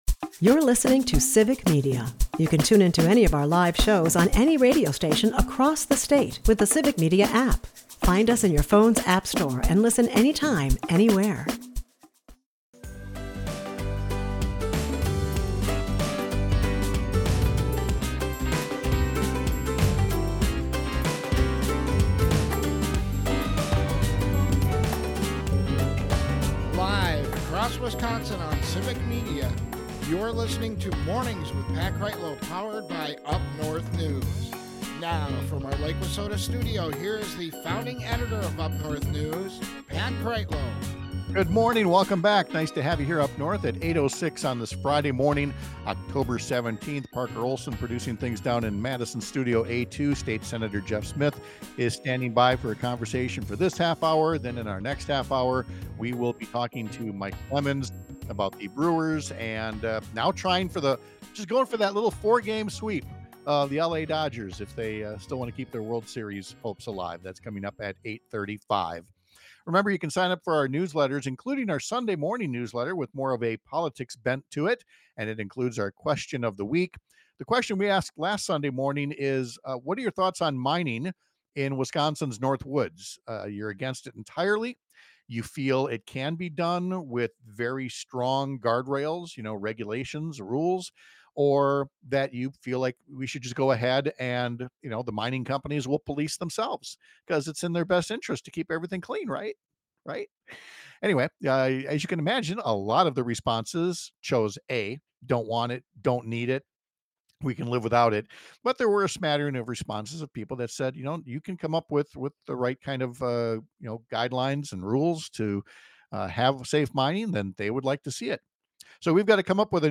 One of them has officially developed in western Wisconsin, where a Republican senator’s home is now located in the new district of a Democratic senator. We’ll ask Democratic Sen. Jeff Smith about the decision by Republican Sen. Jesse James to challenge him next year.